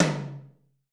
TOM 1L.wav